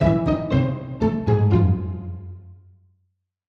Add sound effect to Defeat UI
defeat.mp3